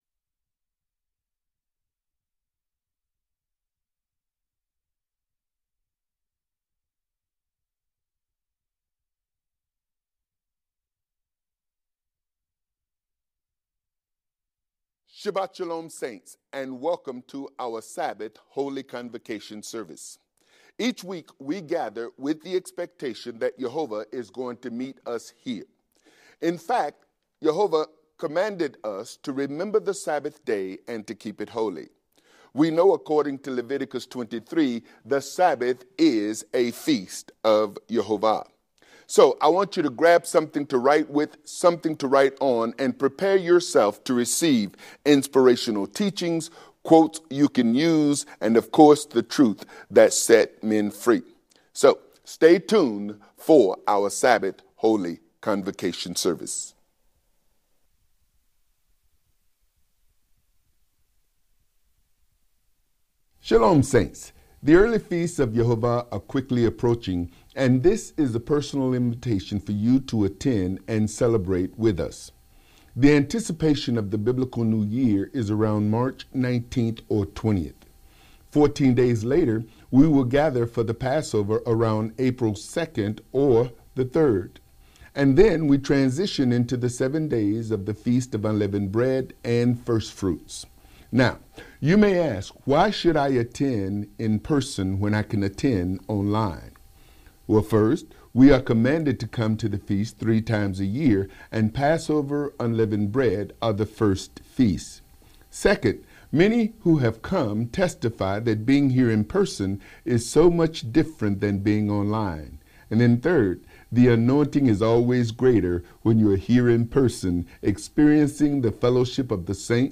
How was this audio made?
Sabbath-Service_-The-Purpose-of-Salvation.mp3